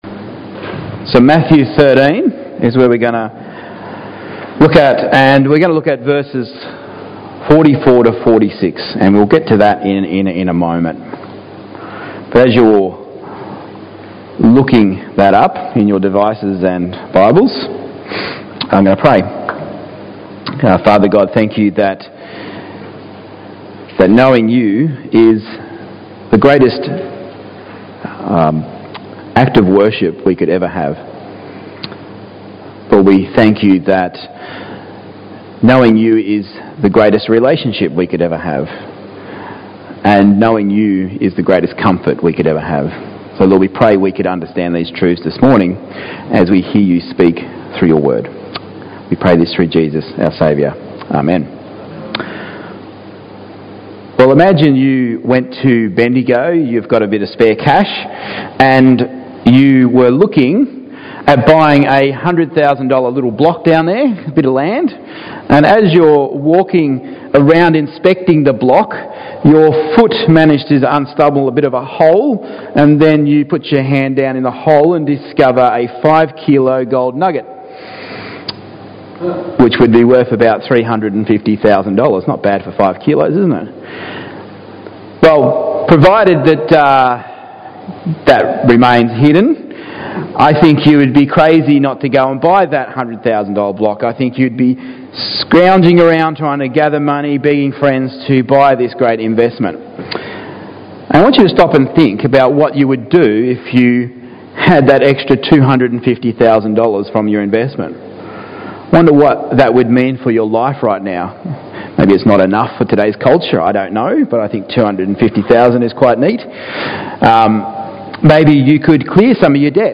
Matthew 13:44-46 Service Type: Morning Gatherings « Are You Captive?